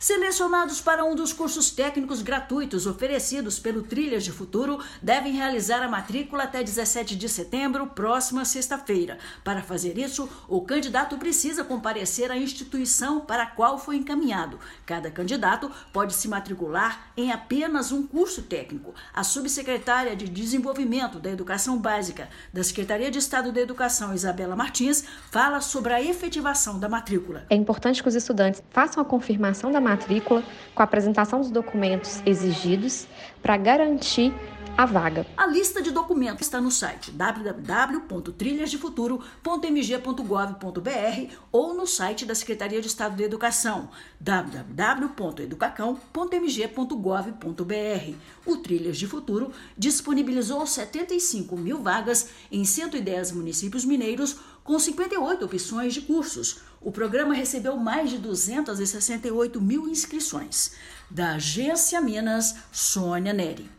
Selecionados para um dos cursos técnicos gratuitos oferecidos pelo Trilhas de Futuro devem realizar a matrícula até a próxima sexta-feira (17/9). Ouça a matéria de rádio.